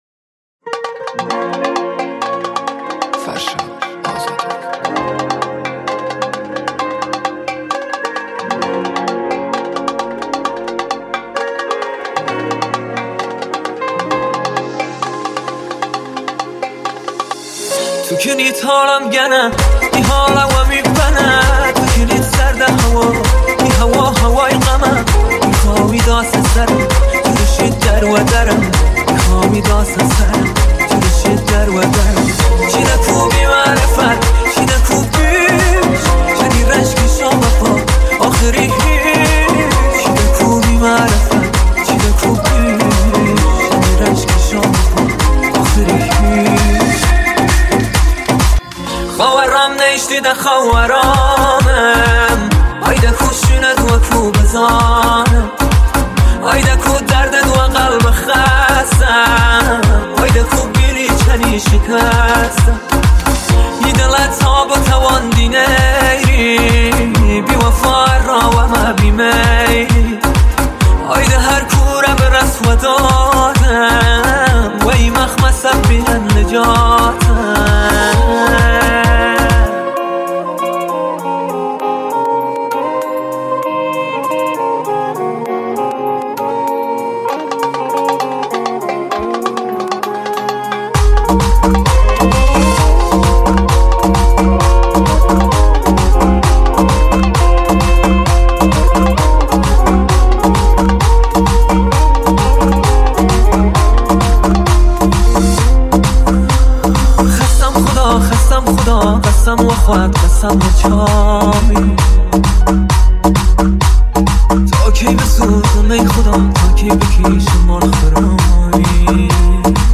محلی کوردی
• ریمیکس